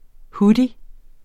Udtale [ ˈhudi ]